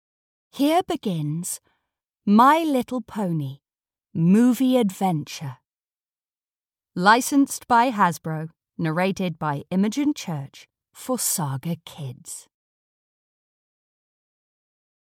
Audio knihaMy Little Pony - The New Generation - Movie Adventure (EN)
Ukázka z knihy